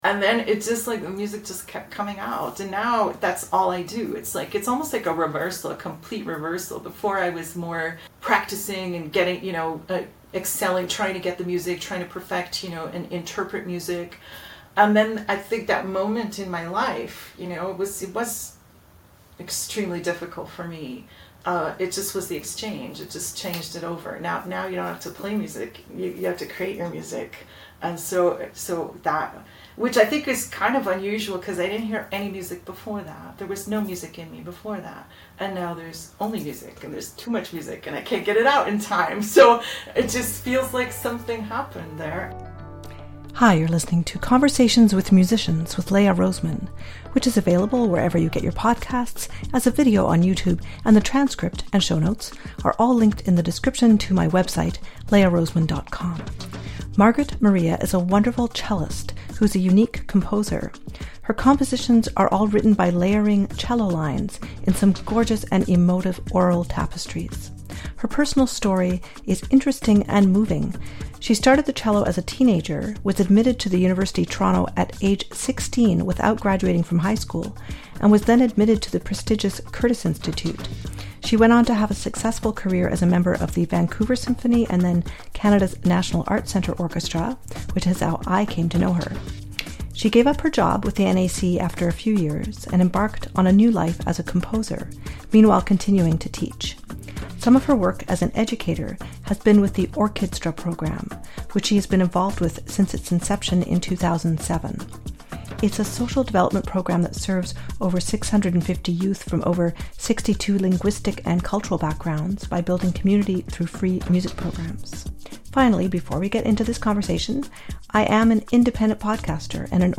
This episode has a lot of powerful music; you can use the timestamps to navigate.